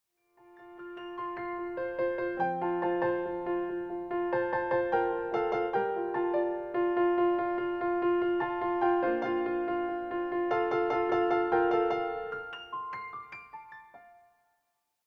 clear and expressive piano arrangements